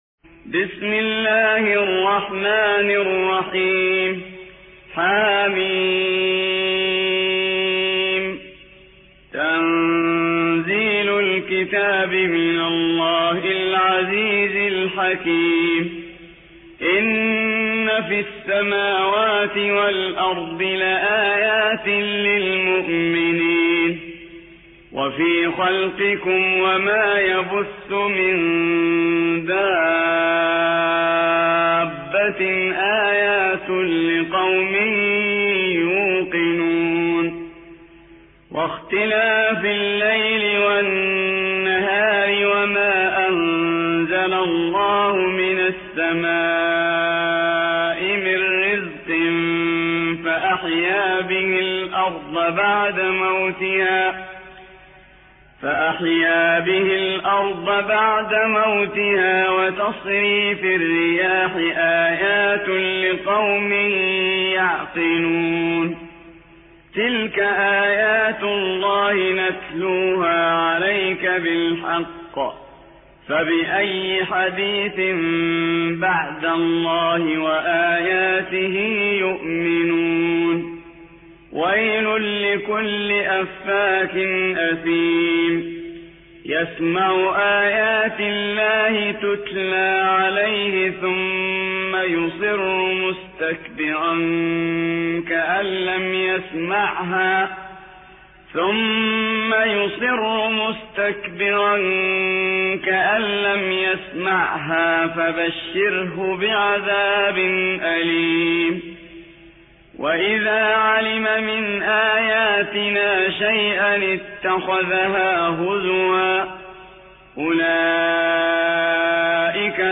45. سورة الجاثية / القارئ